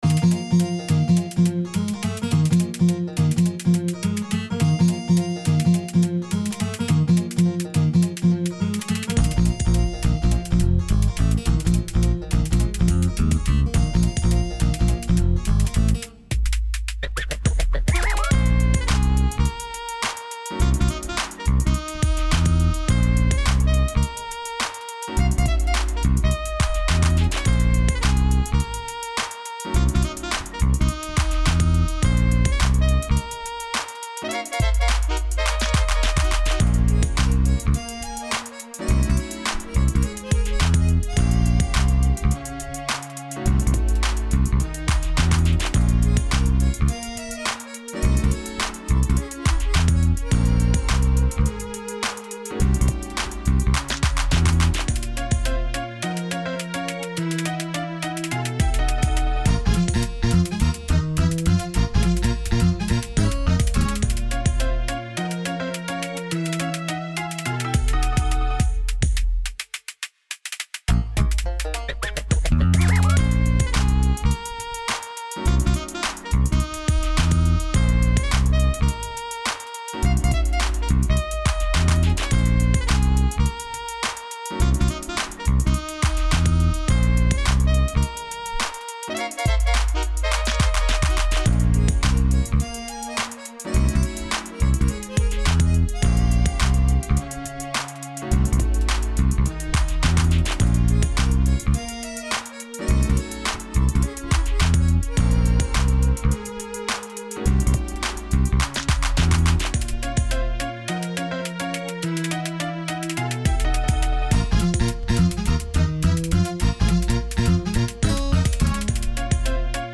hip-hop and jazz
remix